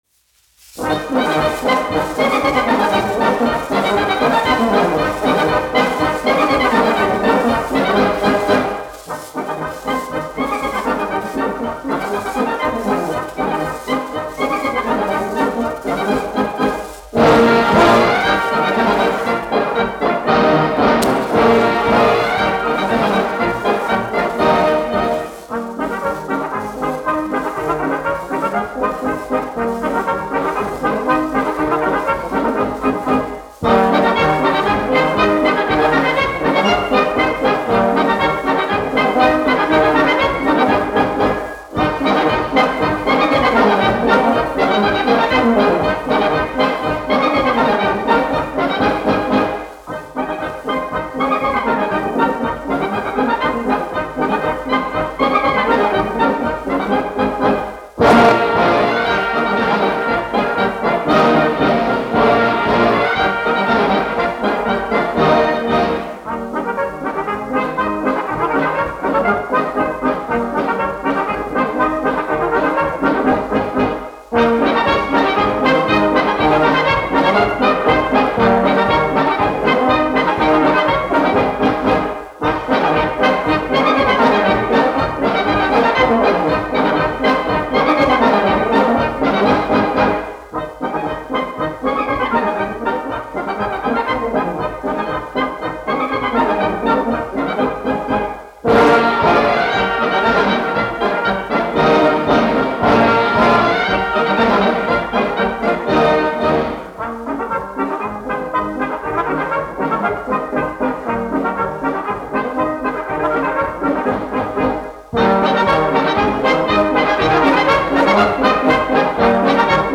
Diždancis : latviešu tautas deja
1 skpl. : analogs, 78 apgr/min, mono ; 25 cm
Latvijas vēsturiskie šellaka skaņuplašu ieraksti (Kolekcija)